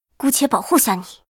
尘白禁区_安卡希雅辉夜语音_支援.mp3